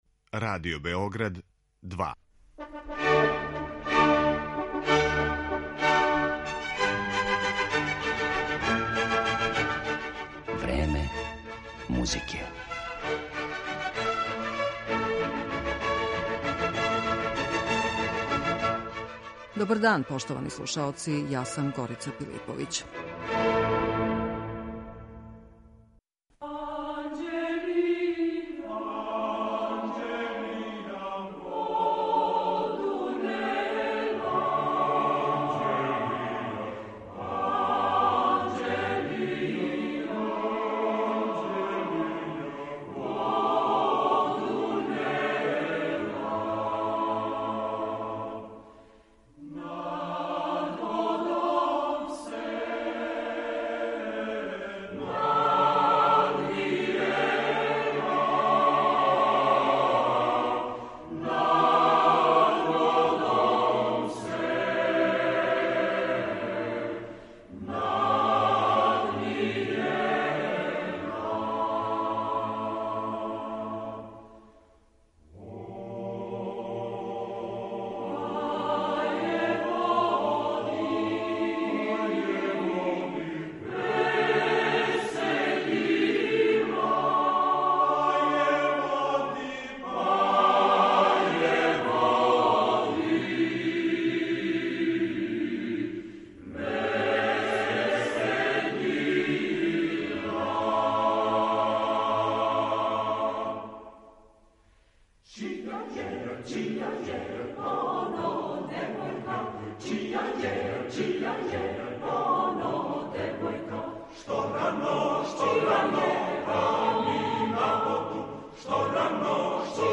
Српска народна песма и концепције националне уметности – била је оквирна тема троје наших музиколога који су говорили на једном од форума Музиколошког института САНУ.
Делове њихових излагања, уз одабране музичке примере наших композитора, можете слушати у емисији Време музике